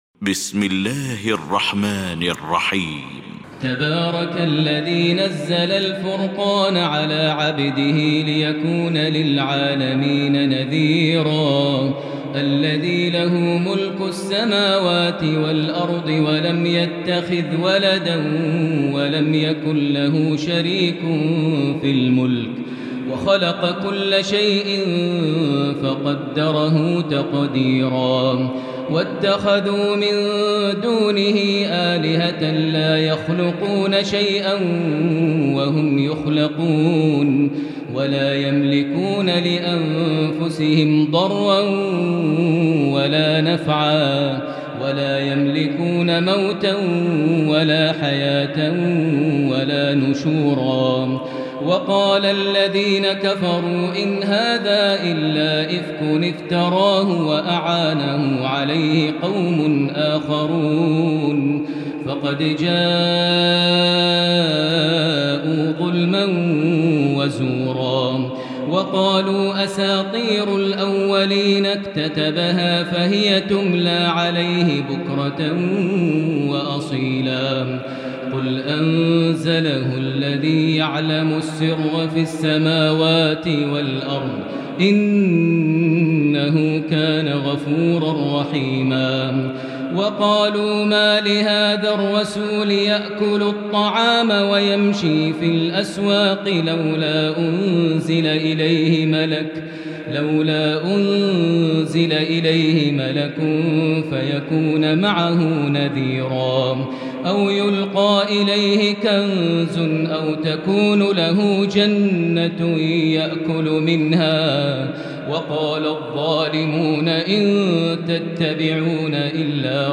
المكان: المسجد الحرام الشيخ: فضيلة الشيخ ماهر المعيقلي فضيلة الشيخ ماهر المعيقلي الفرقان